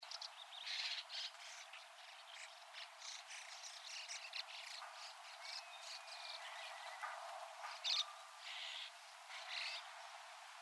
508komukudori_nakigoe.mp3